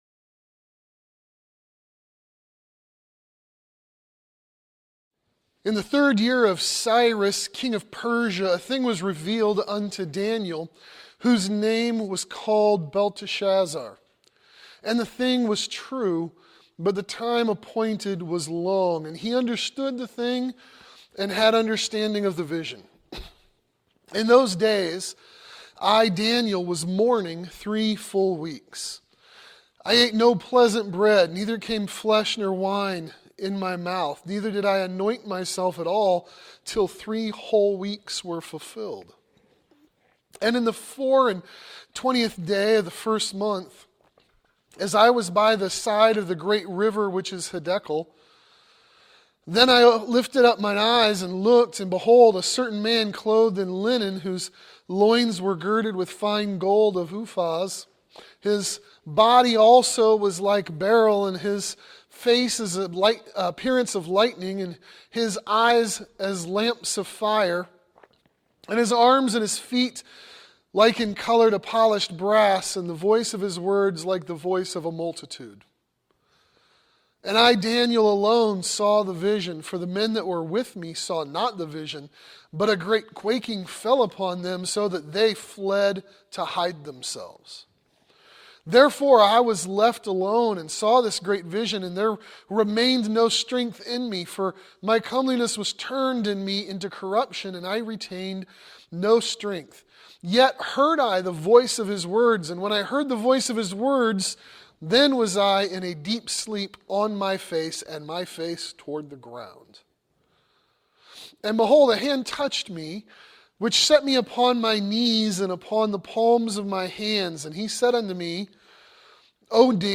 Spiritual Warfare | SermonAudio Broadcaster is Live View the Live Stream Share this sermon Disabled by adblocker Copy URL Copied!